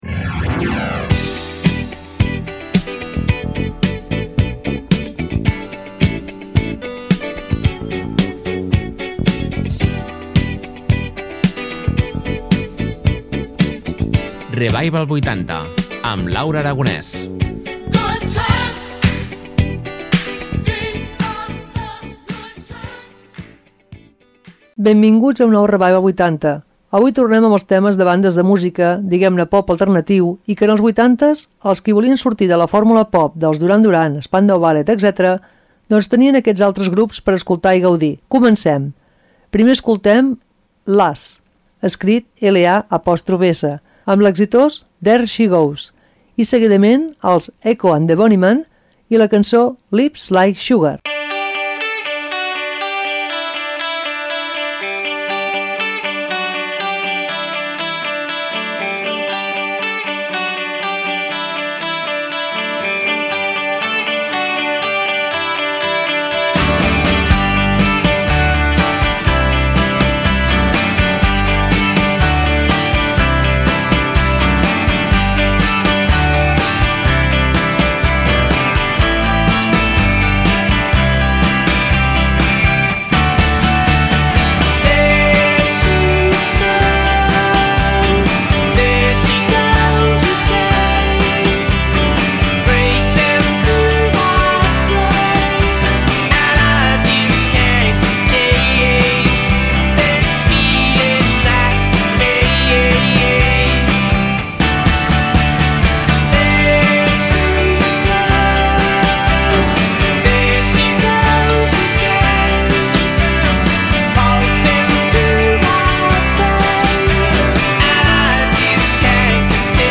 Tornem amb les bandes britàniques, diguem-ne de pop alternatiu , que en els 80’s els qui volien sortir de la fórmula pop dels Duran Duran, Spandau Ballet, etc… tenien aquest altres grans grups.